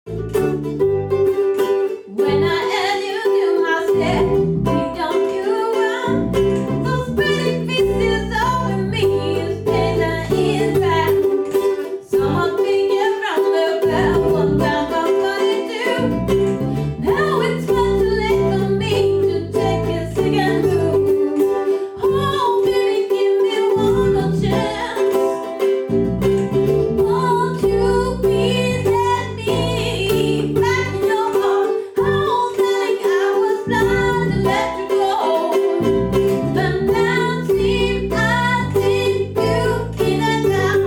Disco / Funk